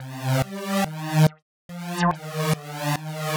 Index of /musicradar/uk-garage-samples/142bpm Lines n Loops/Synths
GA_SacherPad142C-02.wav